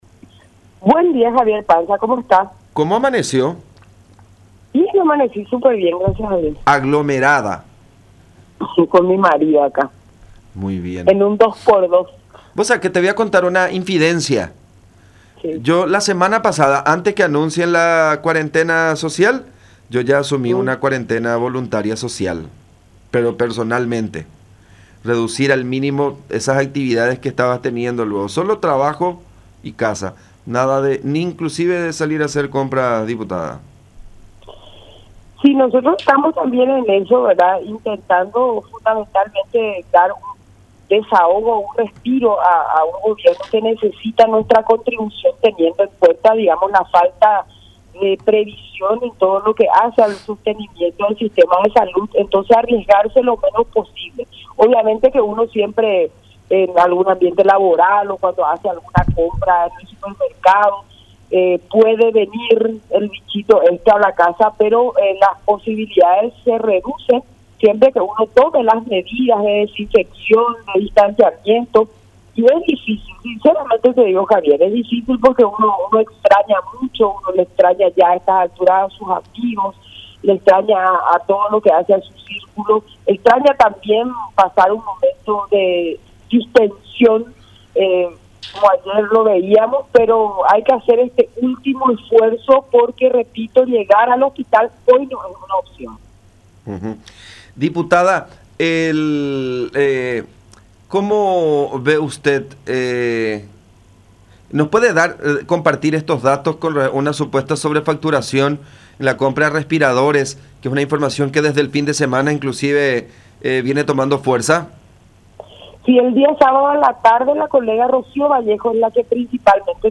“La colega Rocío Vallejo estuvo trabajando en relación a esta denuncia sobre supuesta compra irregular de respiradores. Son 31 ventiladores pulmonares, que se compraron en abril del 2020”, aseveró Kattya González, integrante del grupo de diputados denunciantes del caso, en diálogo con La Unión, agregando que “otra vez está en el ojo de la tormenta DINAVISA (Dirección Nacional de Vigilancia Sanitaria)”, debido a que fue la que respaldó los documentos para las adquisiciones.